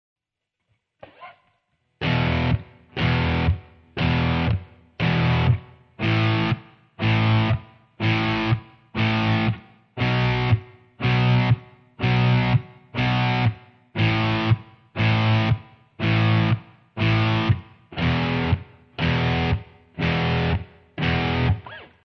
Power chord exercise 1 [MP3]
powechord Ex. 1.mp3